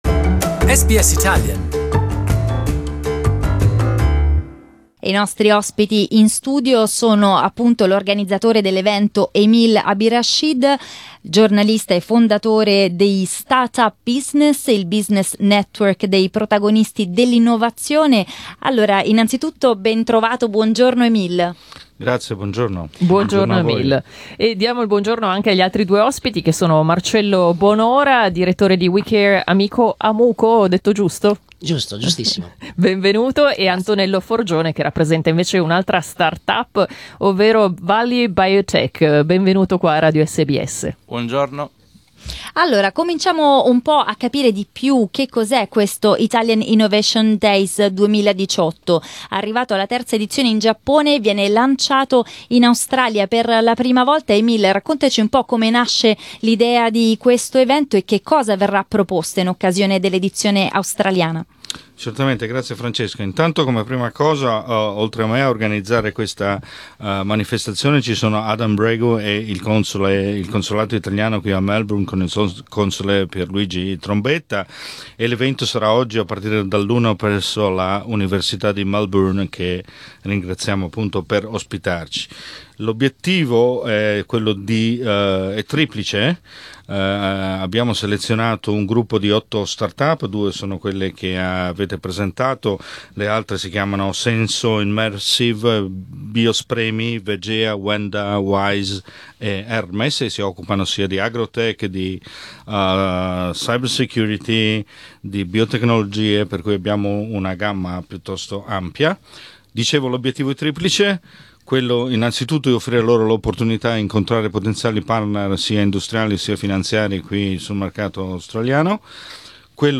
I nostri ospiti in studio sono